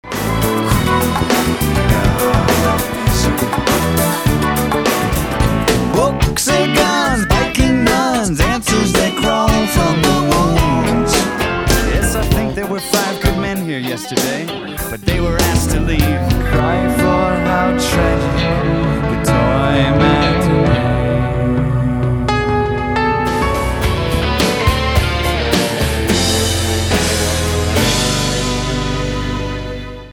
a one-off masterwork of pop music
I hope that someone saves a seat for me on the sampler mix: